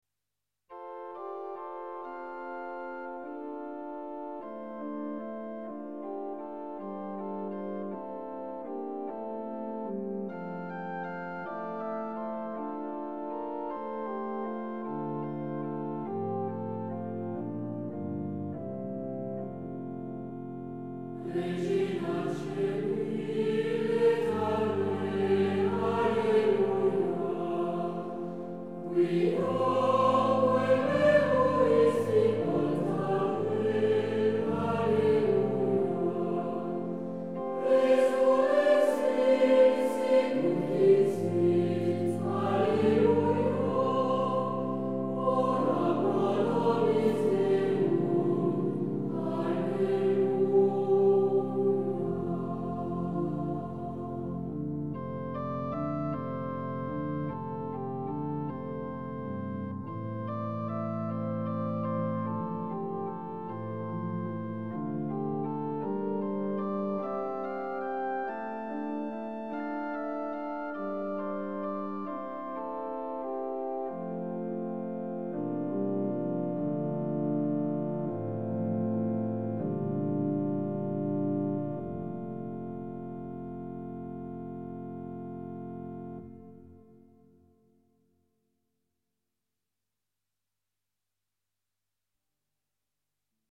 CHANTS GRÉGORIENS